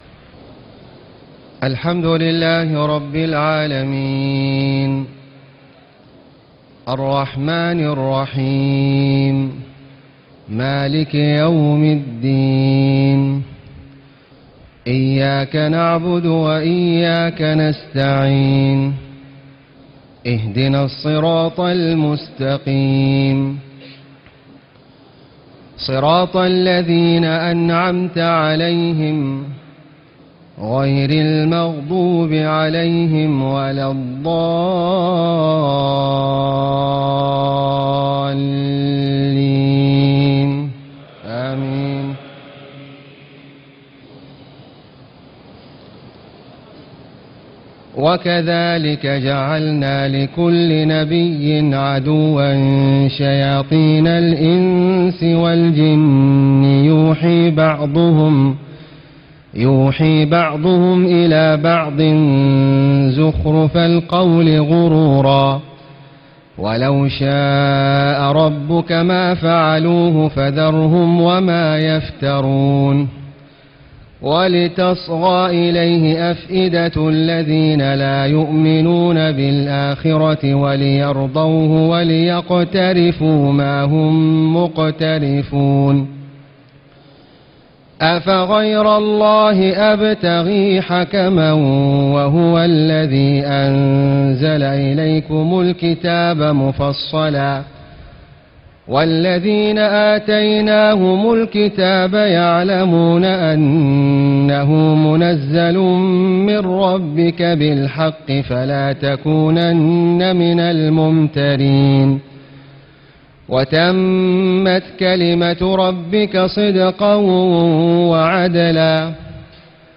تهجد ليلة 28 رمضان 1436هـ من سورتي الأنعام (112-165) و الأعراف (1-30) Tahajjud 28 st night Ramadan 1436H from Surah Al-An’aam and Al-A’raf > تراويح الحرم المكي عام 1436 🕋 > التراويح - تلاوات الحرمين